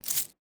Coin Sound.wav